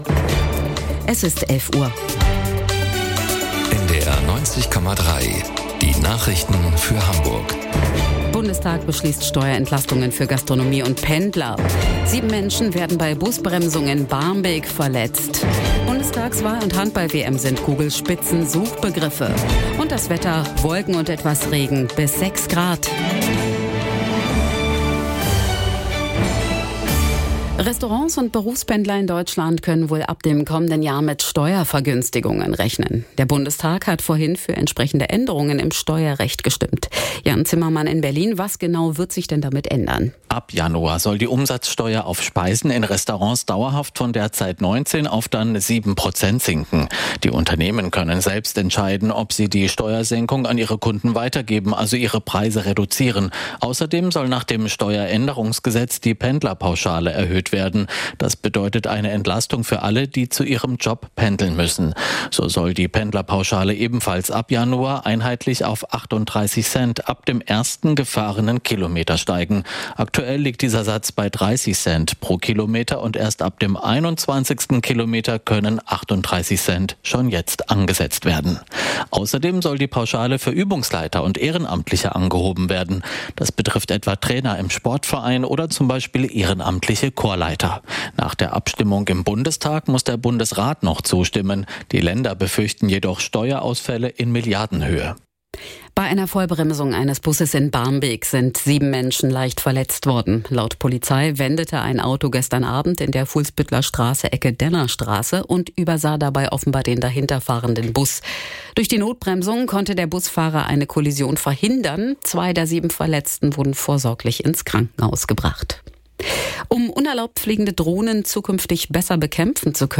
Aktuelle Ereignisse, umfassende Informationen: Im Nachrichten-Podcast von NDR 90,3 hören Sie das Neueste aus Hamburg und der Welt.